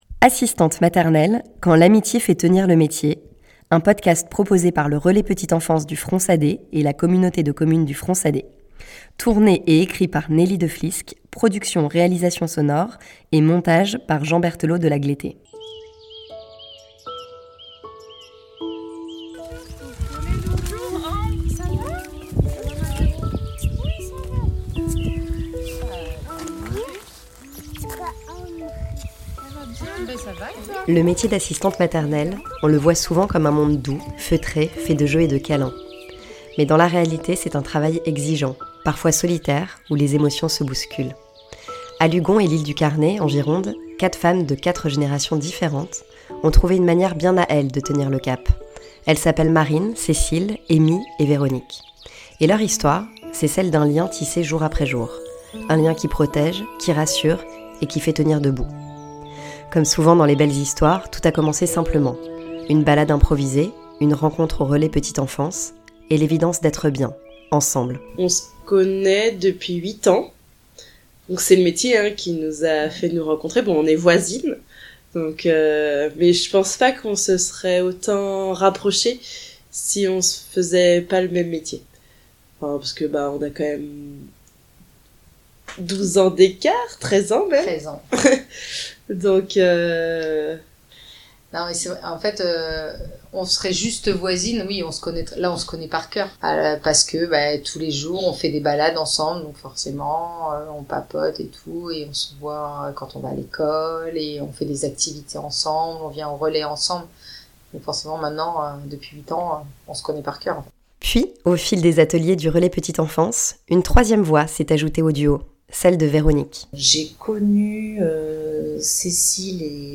Solitude, burn-out, santé mentale, difficulté à poser des limites : leurs voix disent la réalité d’un métier exigeant, mais surtout la force des liens, les éclats de rire et la joie d’avancer ensemble. À travers leurs échanges, se dessine une autre facette du travail du care, collective et profondément humaine.